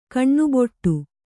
♪ kaṇṇuboṭṭu